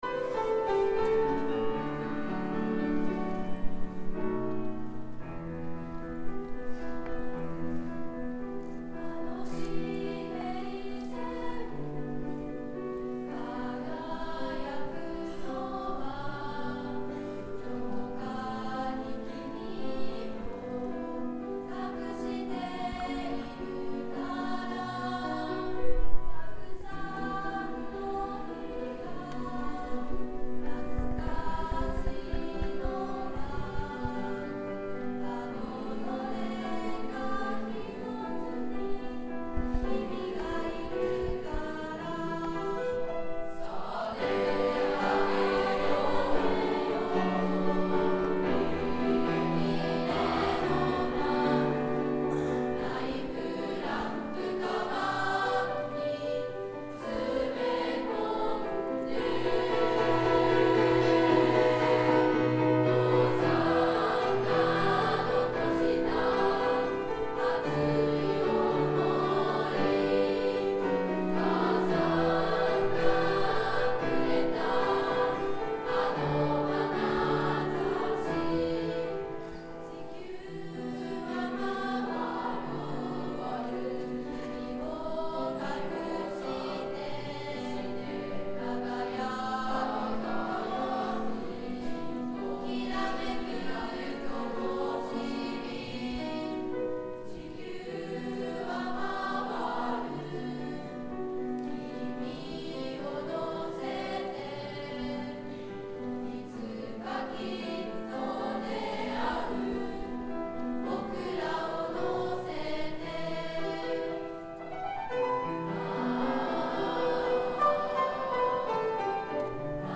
１Ｆ 君をのせて.mp3←クリックすると合唱が聴けます